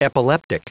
Prononciation du mot epileptic en anglais (fichier audio)
Prononciation du mot : epileptic